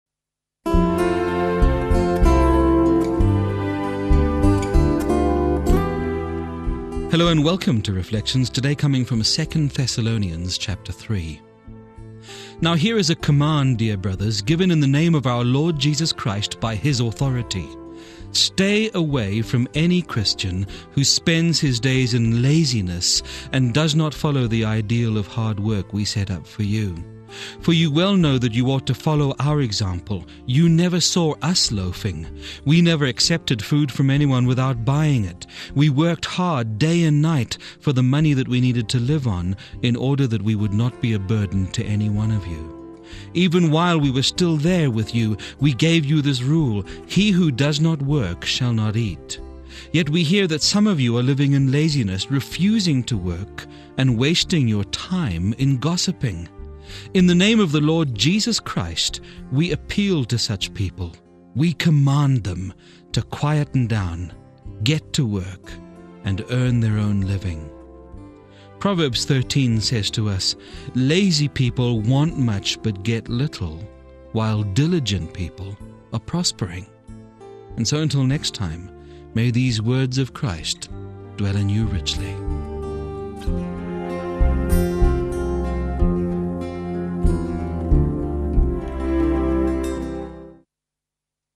A uniquely soothing presentation of God's Word to bring a moment of tranquility into your day.